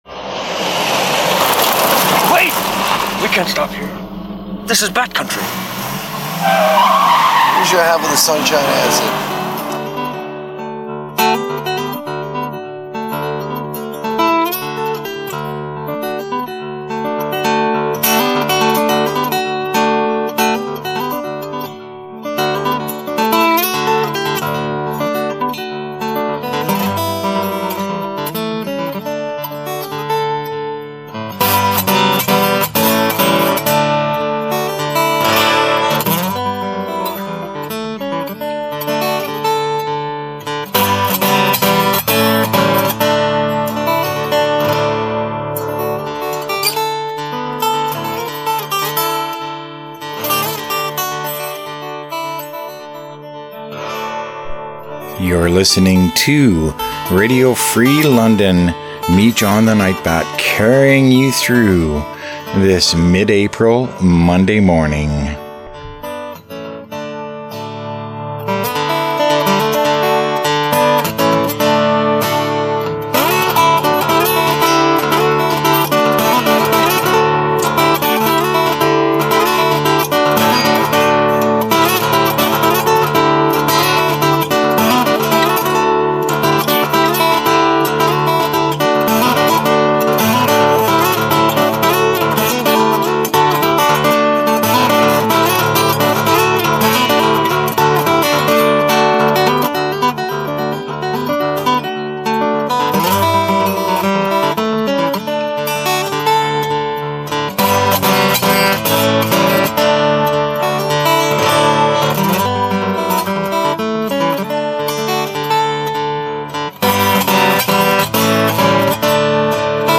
Saint James Easter Sunday Service for April 5th, 2026
Details: First up, as usual, the Sunday Service from St. James Westminster Anglican Church in Wortley Village.
pipe organ and Grand piano
trumpet
The St. James Westminster choir and the congregation